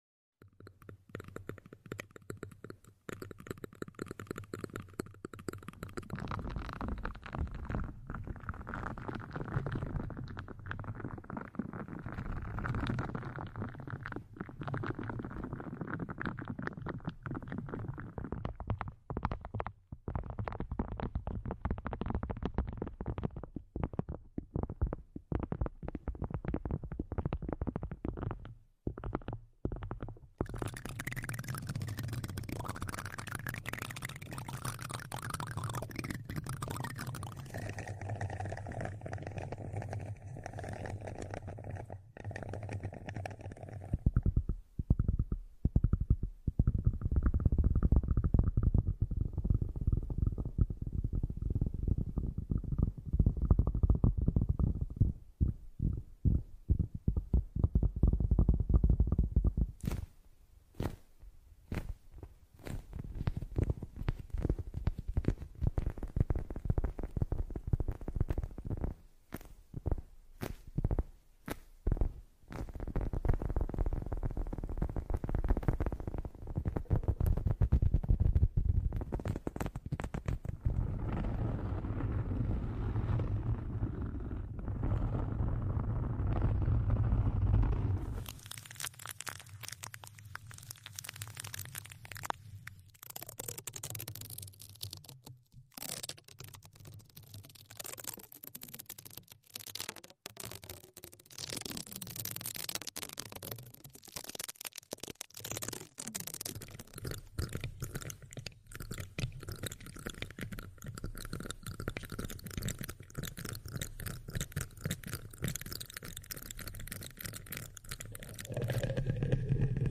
ASMR Relaxe Durma No Talking Sound Effects Free Download